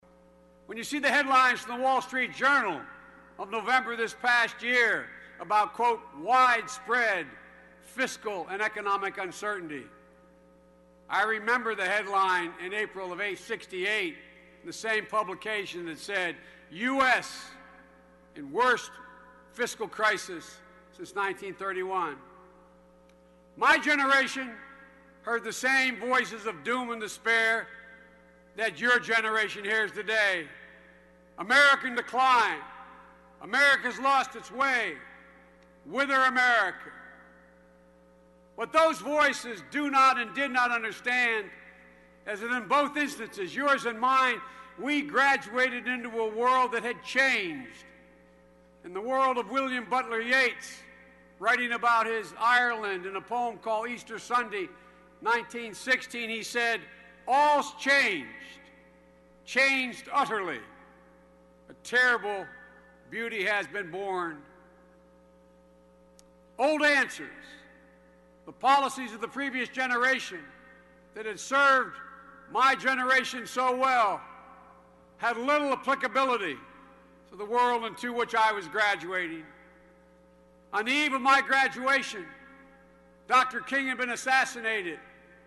公众人物毕业演讲第407期:拜登2013宾夕法尼亚大学(5) 听力文件下载—在线英语听力室